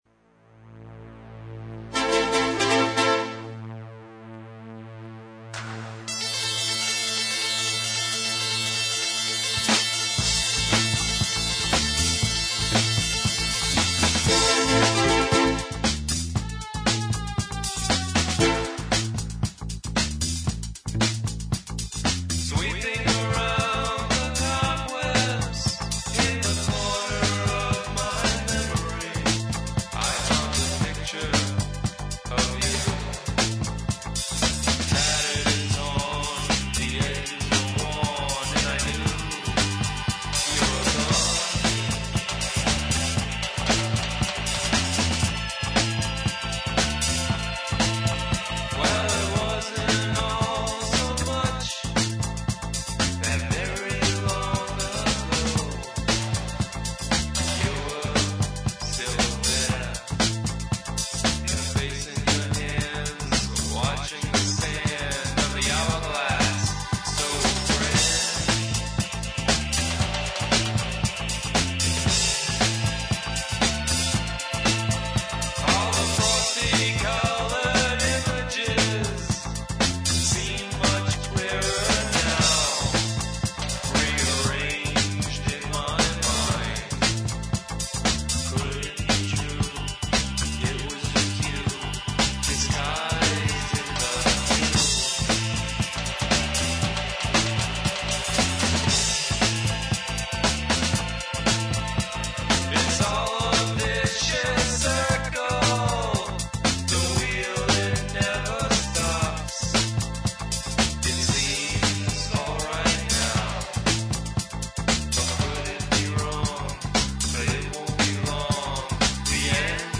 I also had some fun with the vocals.
Drums & Percussion